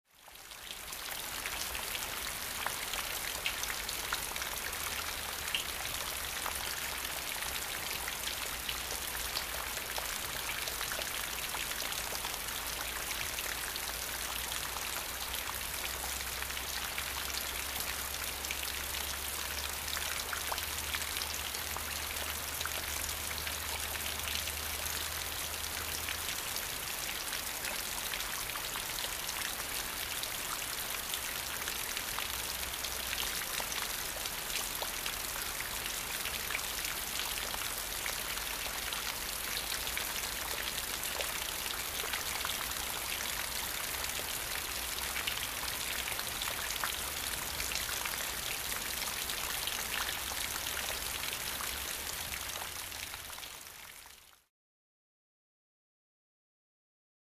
Puddle, Rain | Sneak On The Lot
Rain Falling Heavy And Into A Small Puddle Close Up